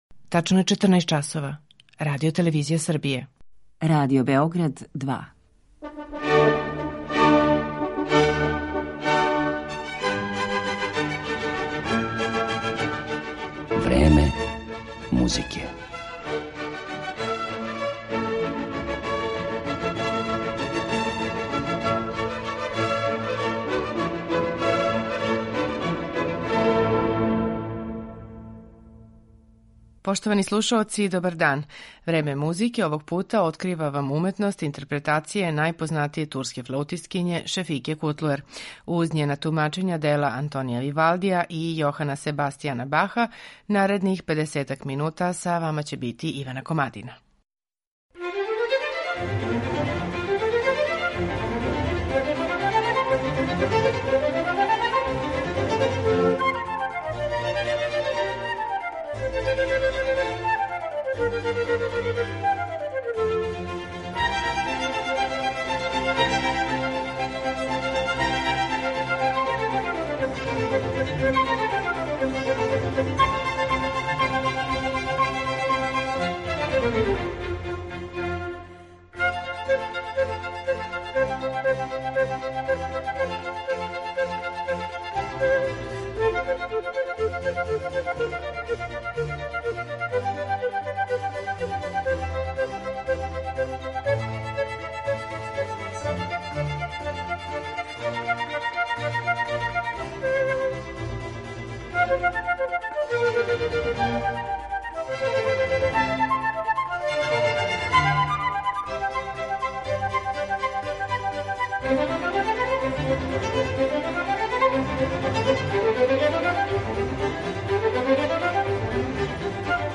супериорне технике и снажне уметничке уверљивости.
специфичној флаутској боји и виртуозности